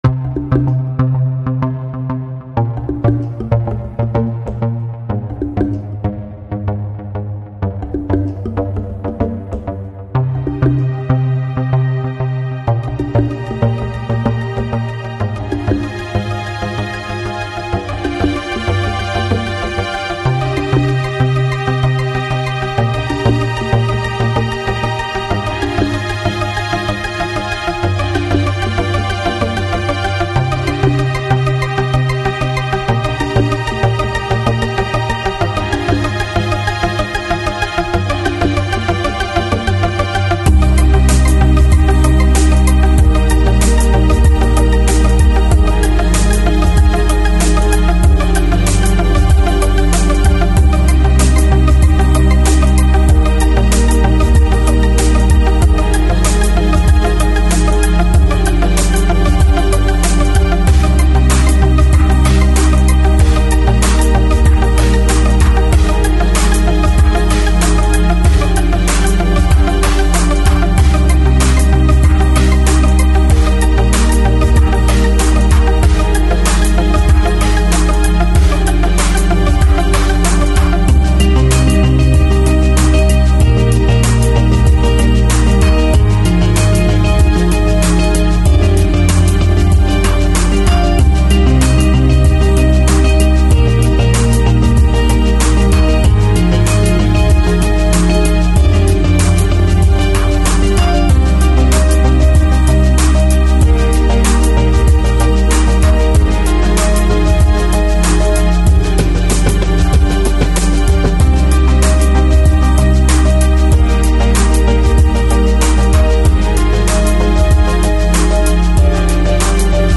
Lounge, Chill Out, Downtempo, Trip Hop, New Age, World